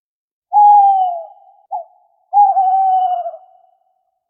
animalworld_owl.ogg